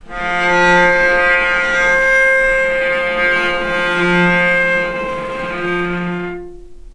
Strings / cello / sul-ponticello / vc_sp-F3-mf.AIF
vc_sp-F3-mf.AIF